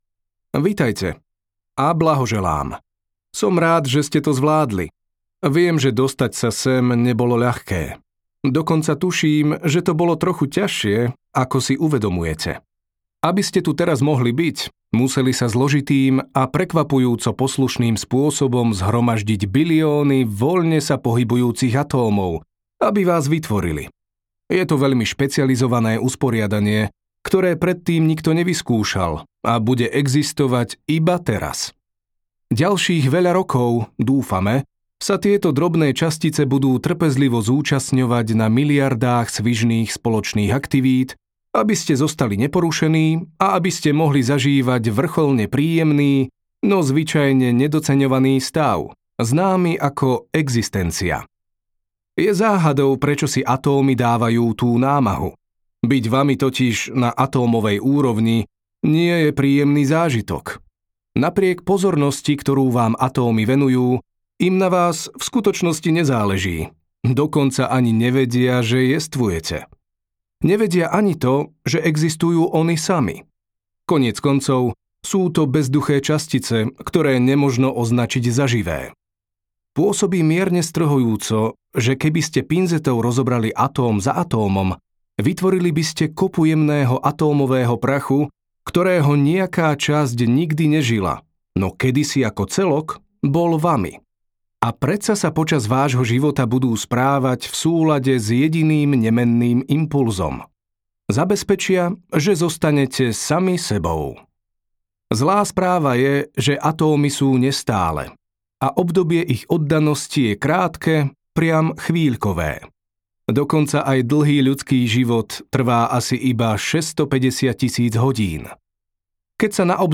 Stručná história takmer všetkého audiokniha
Ukázka z knihy